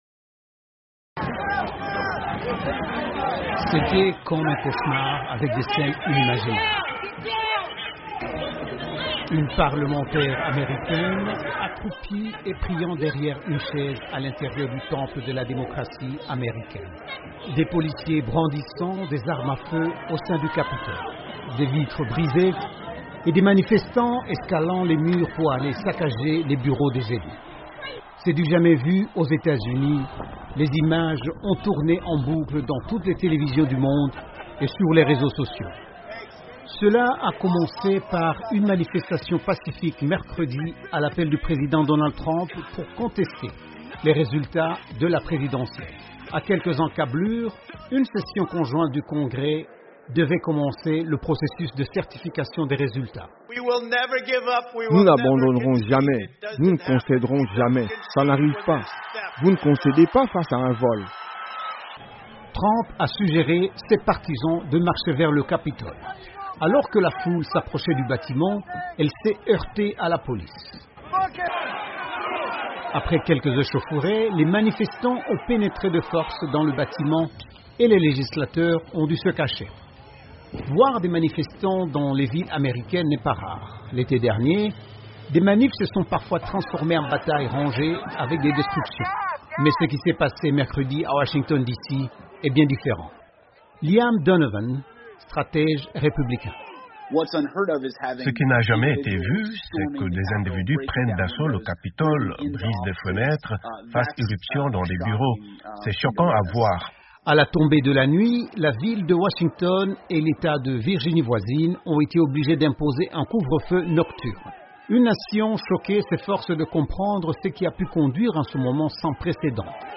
Des manifestants en colère ont envahi hier le Capitole, le parlement américain, grand symbole de la démocratie. Selon le chef de la police, 4 personnes ont été tuées dont une femme. Un reportage